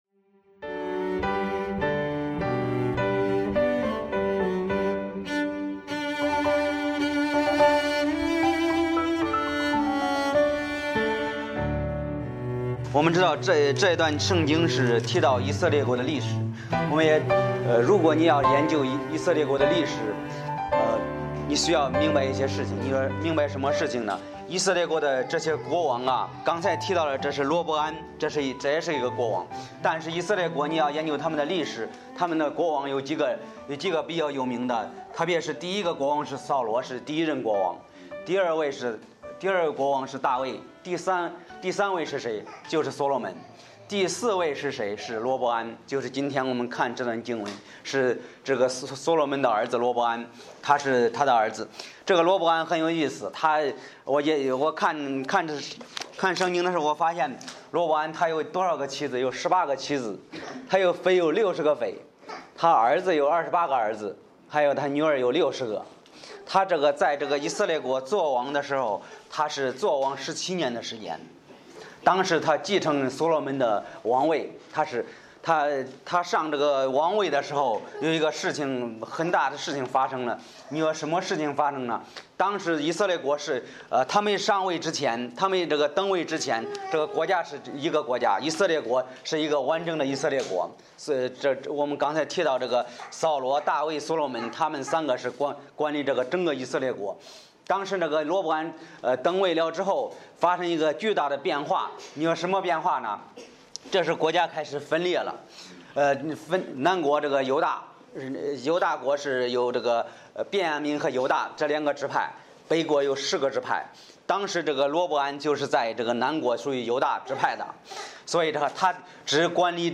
Bible Text: 历代志下12：1-12 | 讲道者